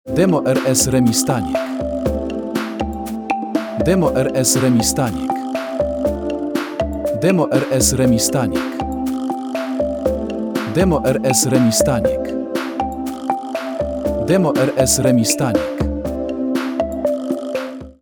elegancki i profesjonalny jingiel reklamowy
Delikatne, inspirujące dźwięki
🎶 Brzmienie, które ożywia i zapada w pamięć
Podkreśl swój przekaz dźwiękiem pełnym energii i harmonii.